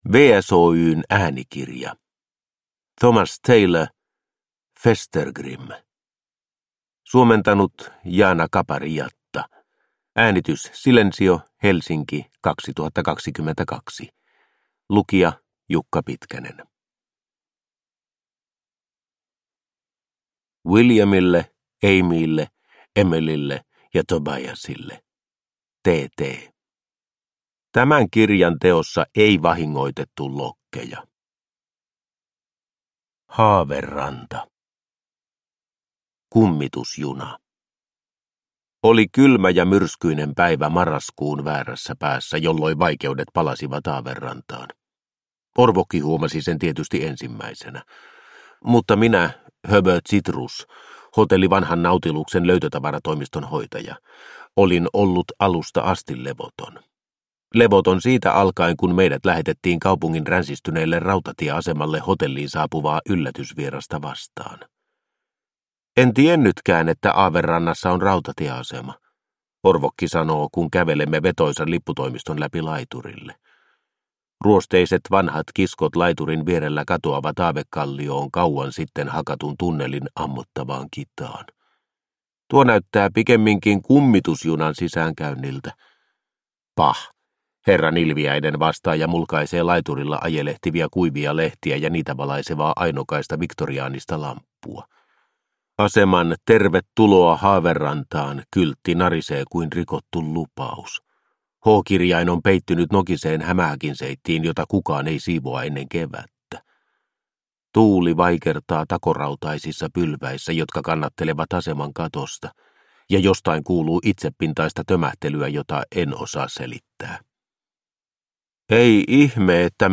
Festergrimm – Ljudbok – Laddas ner